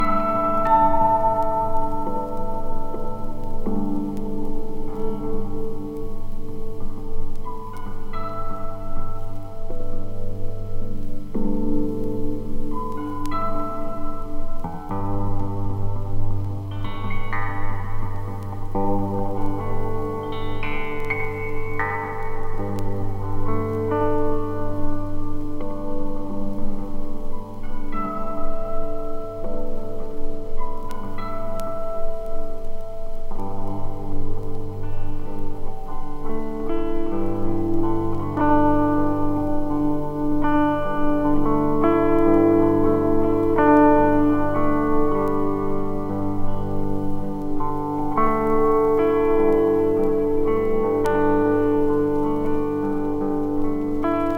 柔らかな感触と、ピンした空気感が同居した不思議なコンテンポラリージャズ。